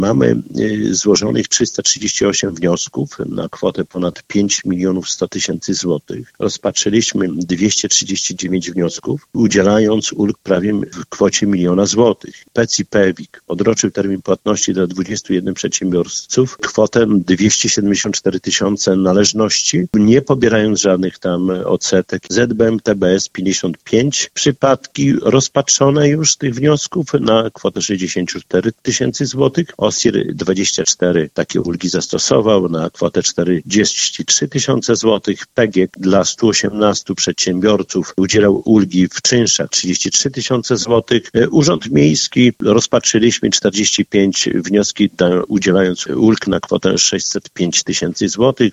Łącznie miasto udzieliło ulg w kwocie ponad miliona złotych. Szczegóły przedstawił Czesław Renkiewicz, prezydent Suwałk.